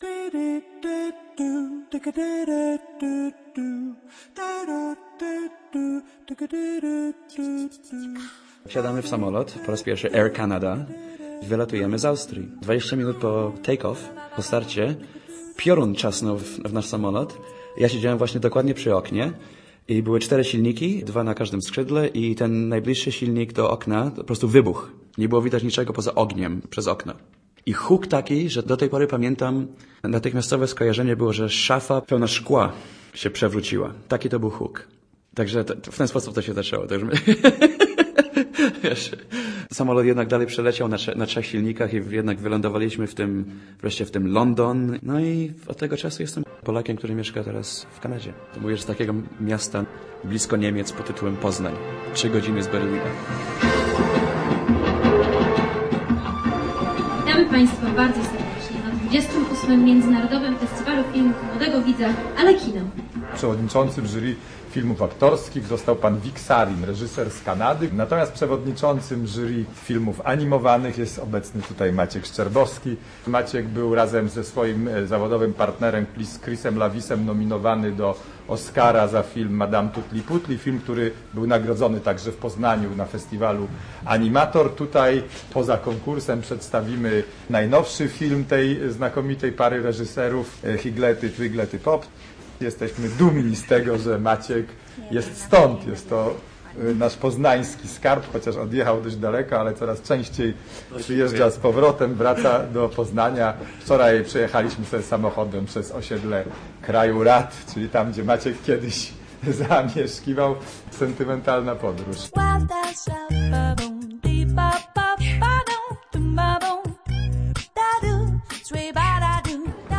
Życie to musi być coś więcej - reportaż